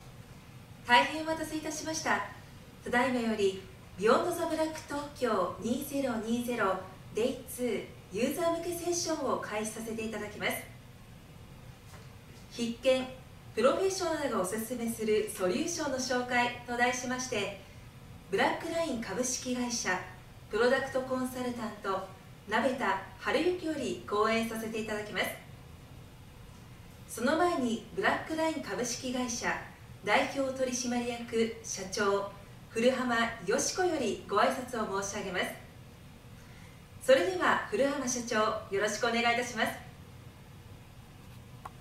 ナレーター｜MC｜リポーター